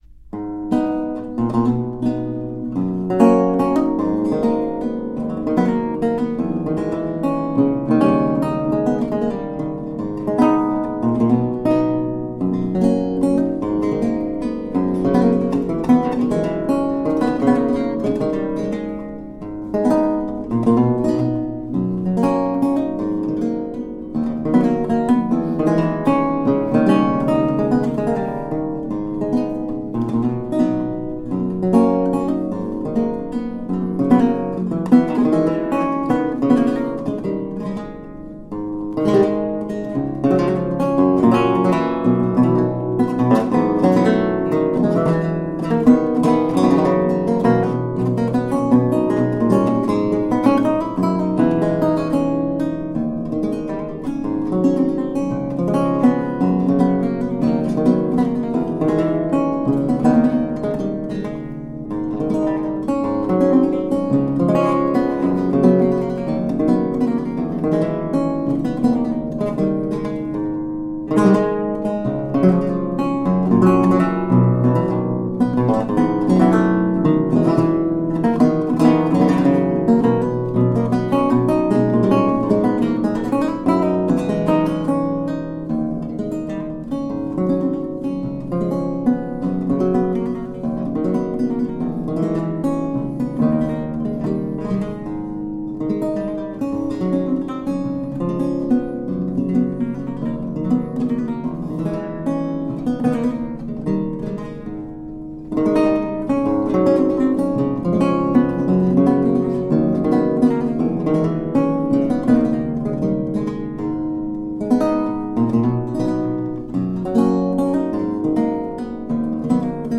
A marvelous classical spiral of lute sounds.
Classical, Baroque, Instrumental Classical
Lute